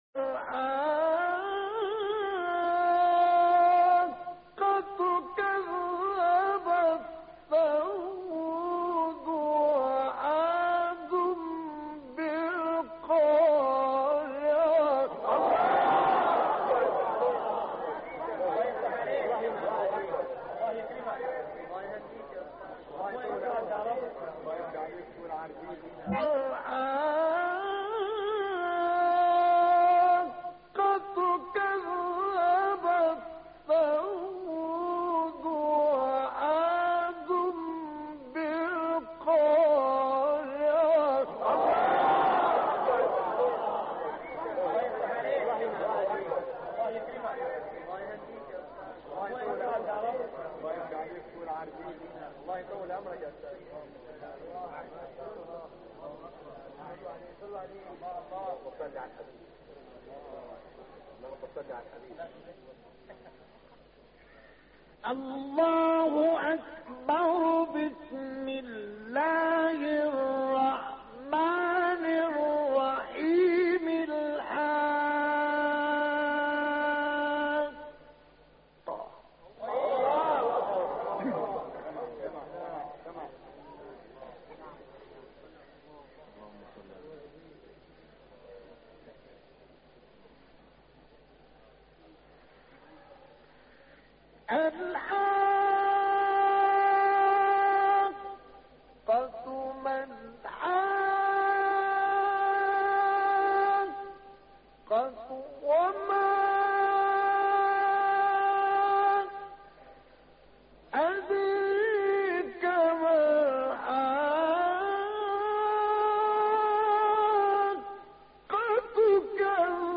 تلاوت مجلسی آیات 1-12 سوره الحاقه توسط مصطفی اسماعيل
اكبرالقراء استاد مصطفي اسماعيل از مشاهير و نوابغ علم قرائت قرآن در جهان اسلام است كه كلام خدا را با آهنگي بسيار لطيف و دلنشين و با الحاني منطبق با معاني و مفاهيم الهي تلاوت مي كند .